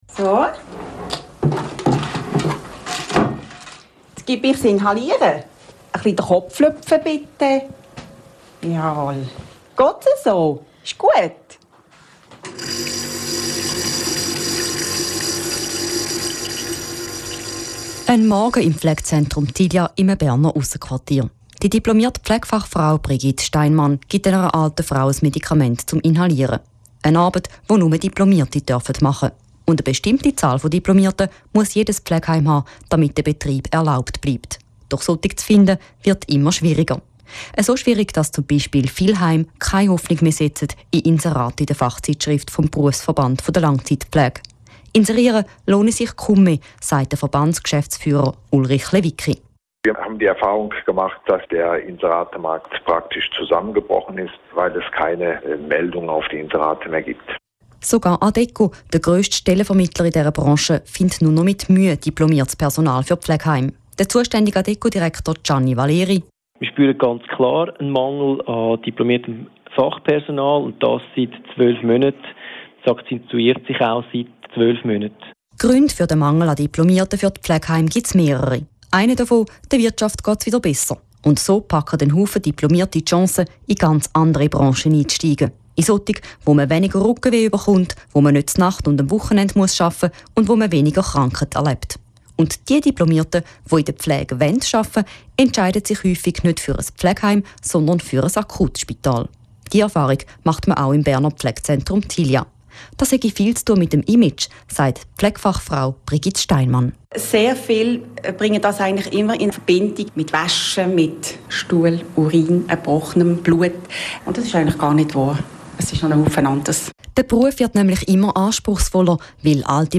Wenn ältere Menschen in der Schweiz Pflegefälle werden beginnen die Probleme. Es gibt schlicht zu wenig diplomieretes Personal, das in den Alters-Pflegeheimenen arbeitet. Die Reportage